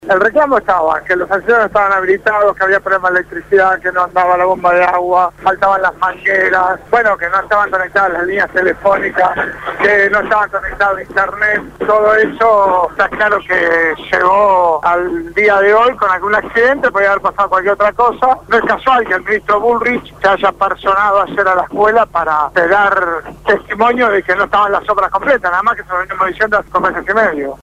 Quién participó del abrazo al Normal 7- habló esta mañana con el programa «Punto de Partida» (Lunes a viernes de 7 a 9 de la mañana) por Radio Gráfica FM 89.3